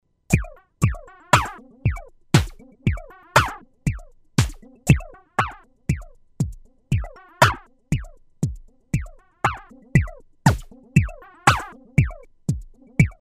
VA drum
Class: Synthesizer